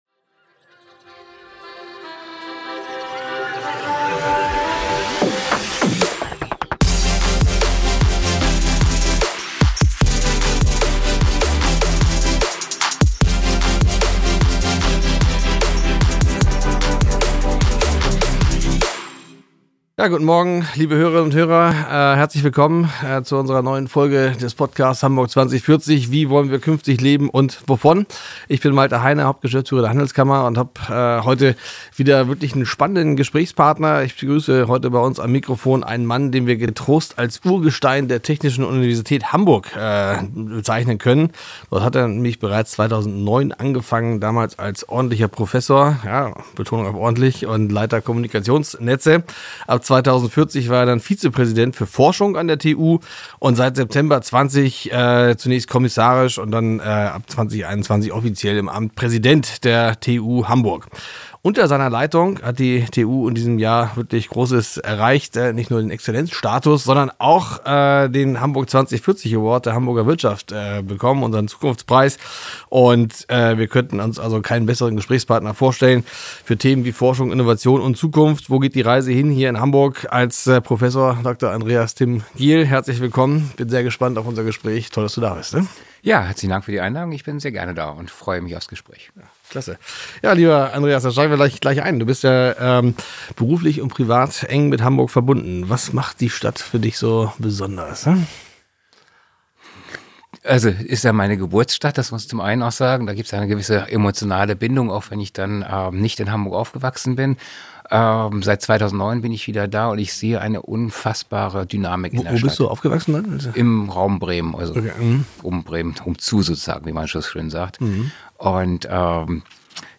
Sie beleuchten, wie internationale Vernetzung, Exzellenzstatus und erfolgreiche Ausgründungen den Wissenschafts- und Wirtschaftsstandort Hamburg stärken. Außerdem diskutieren sie, warum die enge Zusammenarbeit von Universitäten, Start-ups und etablierten Unternehmen entscheidend ist, um Hamburg als Innovationsmetropole zu positionieren – und welche Impulse aus dem Süden der Stadt für die gesamte Region ausgehen können.